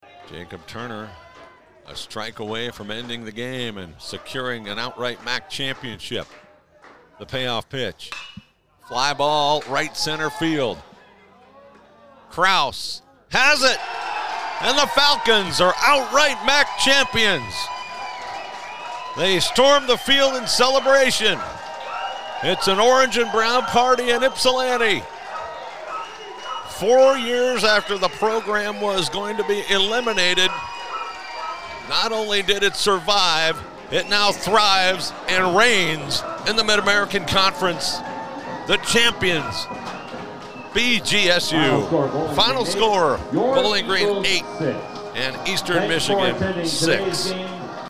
FINAL CALL